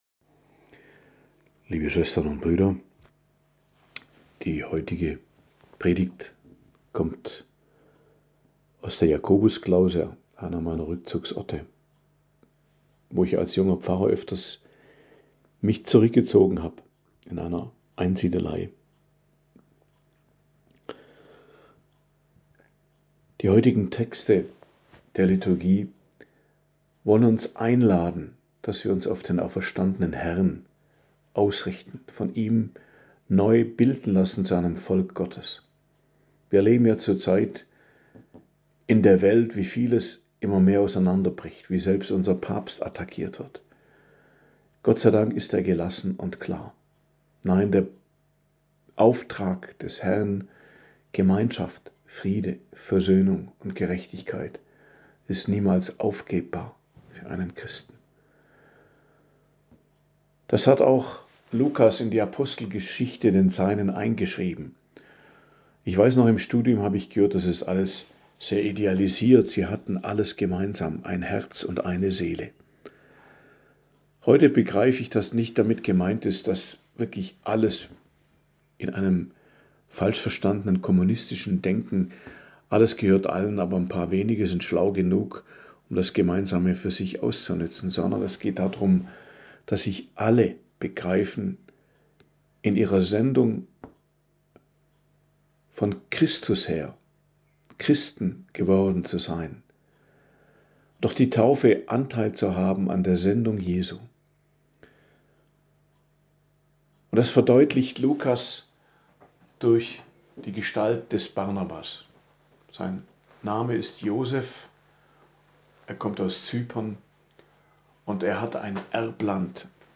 Predigt am Dienstag der 2. Osterwoche, 14.04.2026